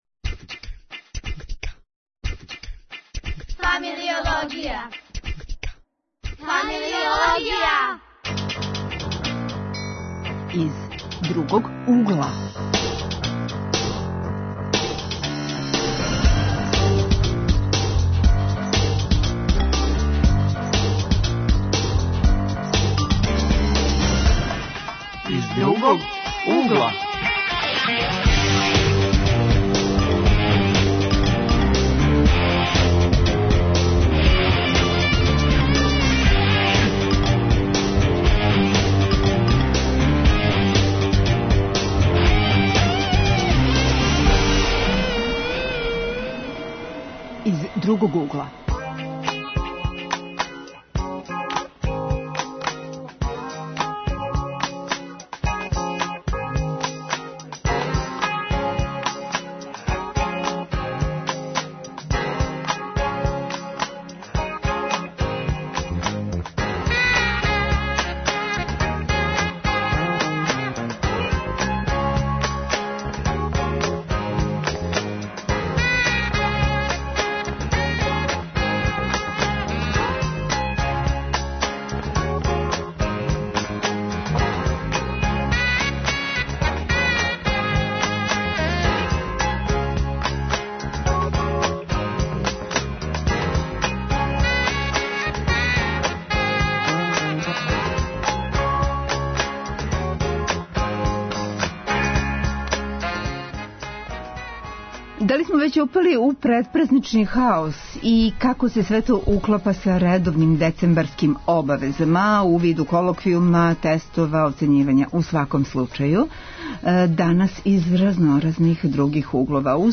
Гости -студенти.